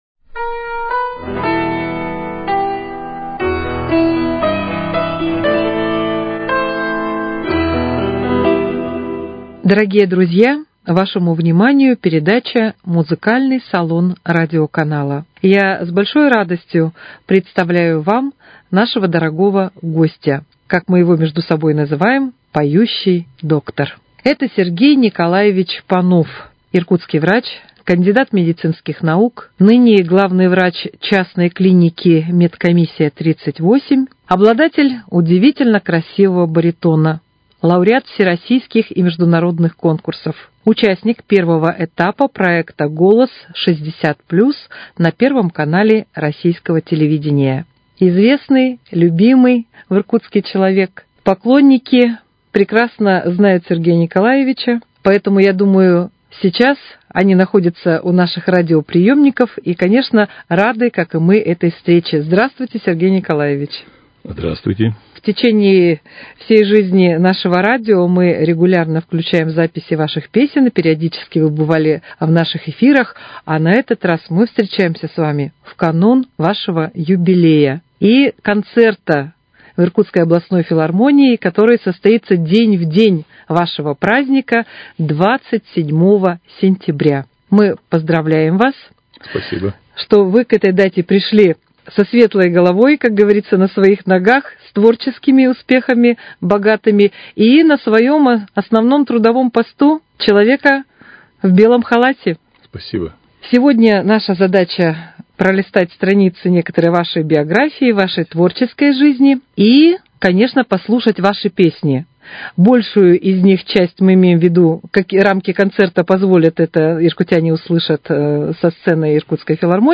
Он обладает прекрасным баритоном, является лауреатом всероссийских и международных конкурсов и участвовал в проекте «Голос 60 +» на Первом канале.